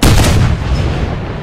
fireenemy2.ogg